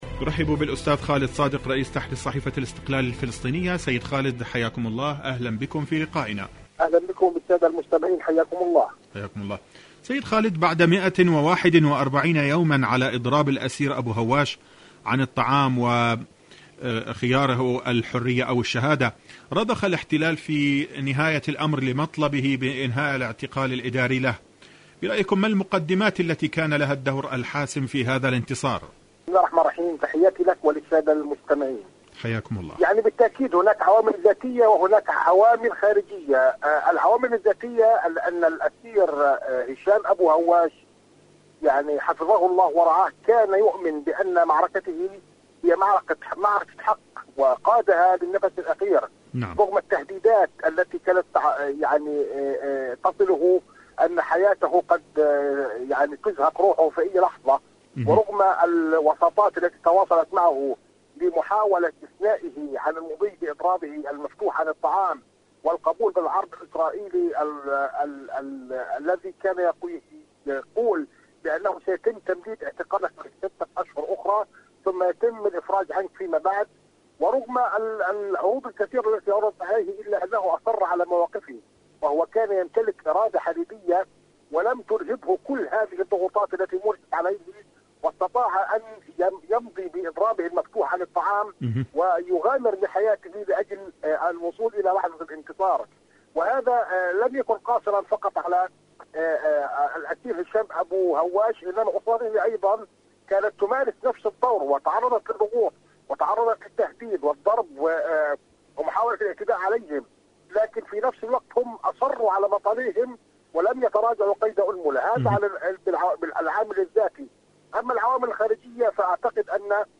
إذاعة طهران-فلسطين اليوم: مقابلة إذاعية